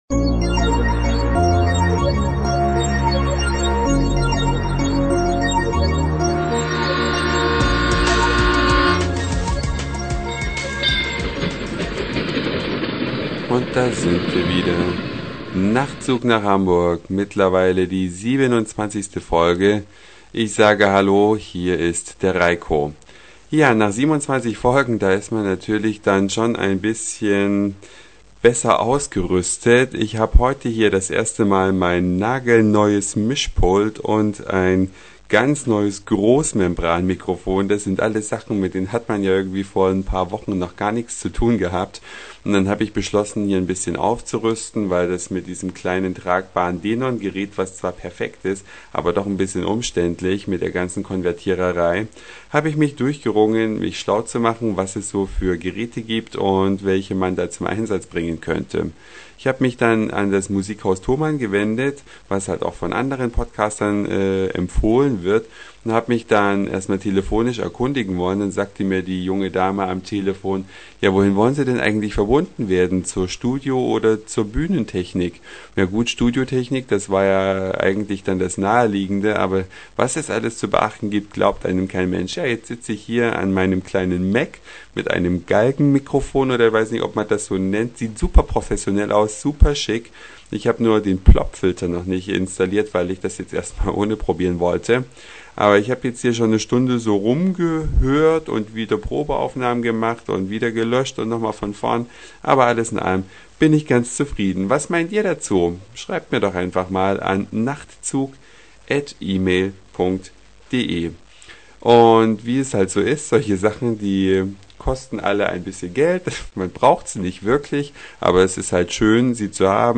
Folge Nach 26 Folgen ohne Mischpult und Großmembranmikrofon